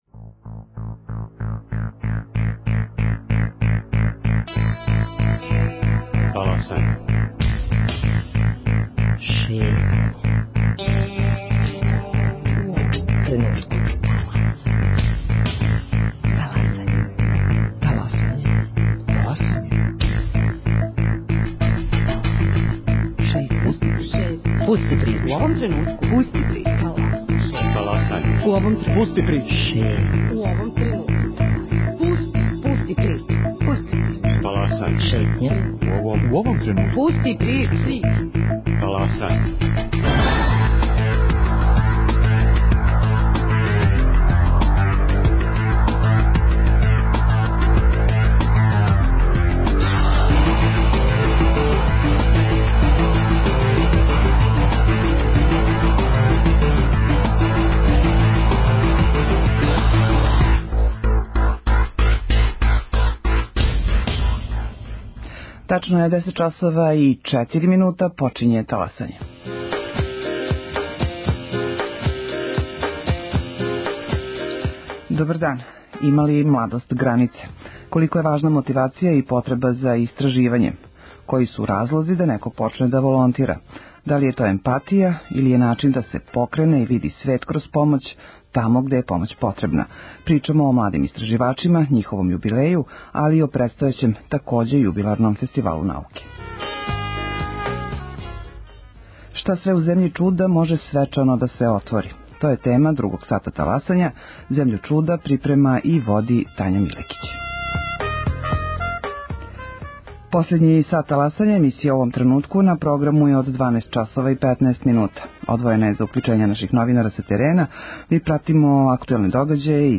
У другом делу емисије у сусрет Фестивалу науке,такође јубиларном, 10-том по реду, говоре научници који ће на овој манифестацији представити институције из којих долазе, факултете, институте, научне часописе и сва чуда науке, као такве.